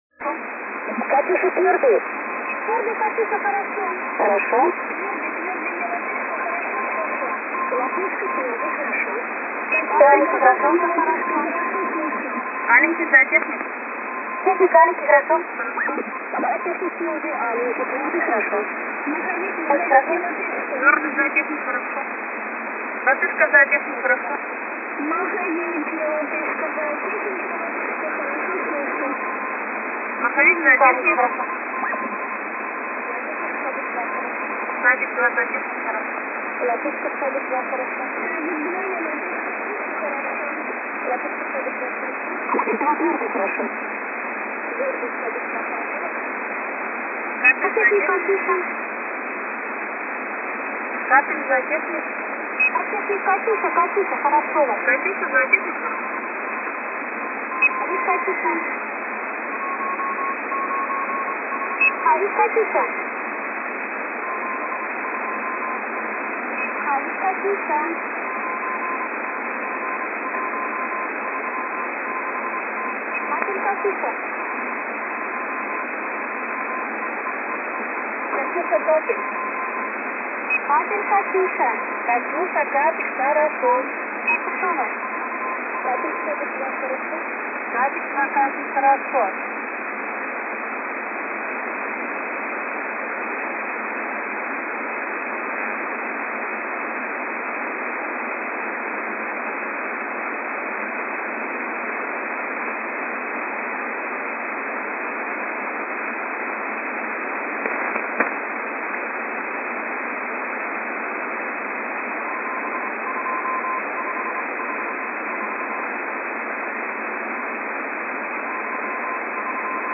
6945usb with s06 in background
Lithuania